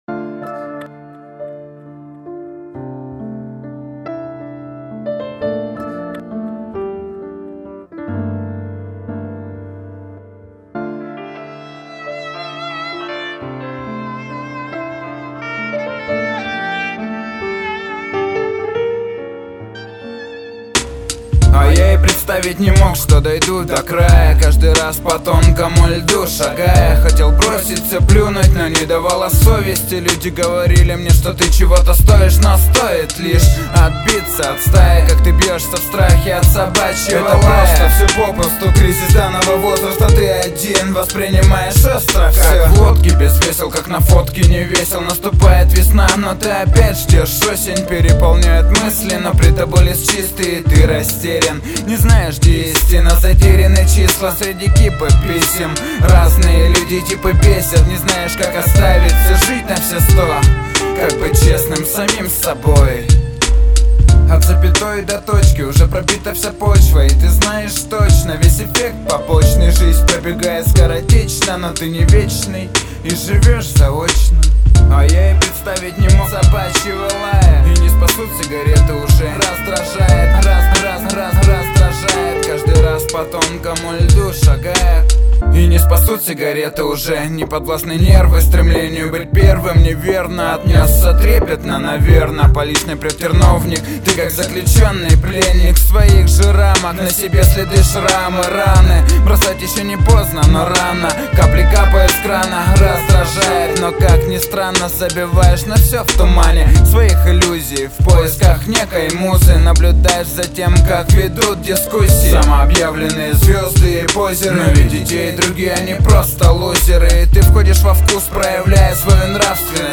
Рэп (46679)